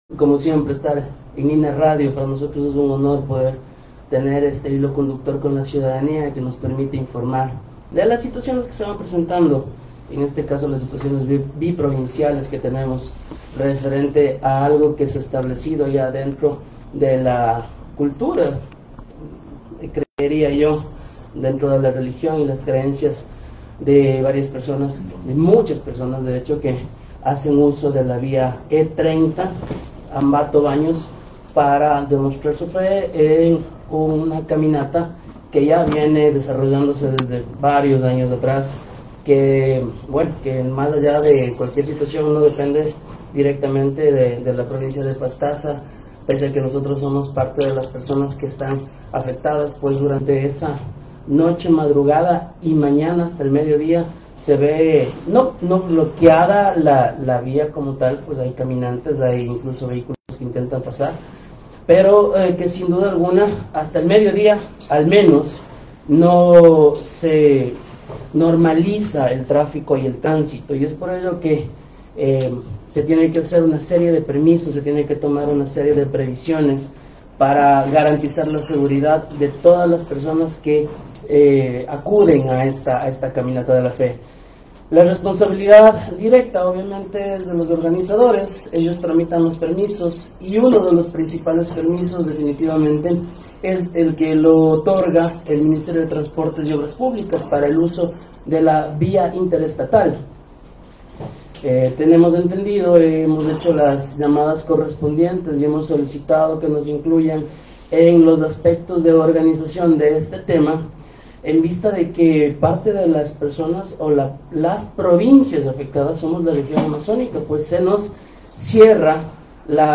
Rolando Ramos, gobernador de Pastaza.